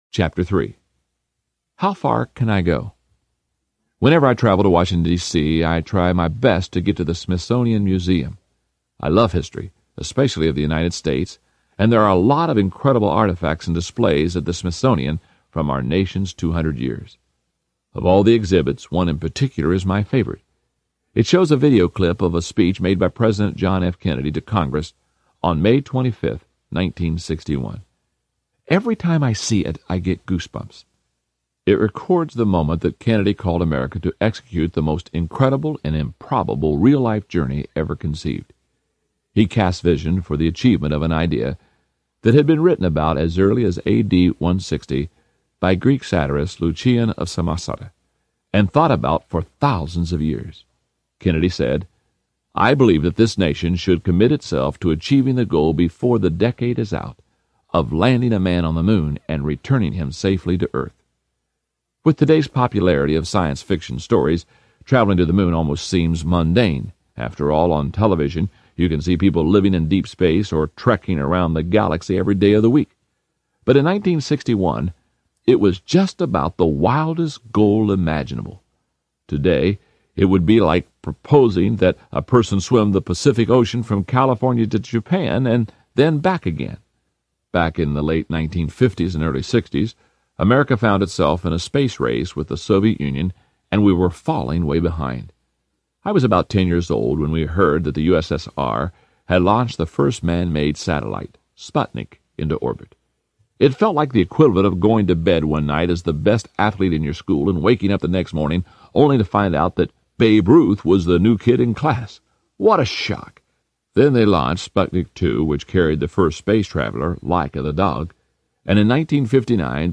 The Success Journey Audiobook
Narrator
3.0 Hrs. – Unabridged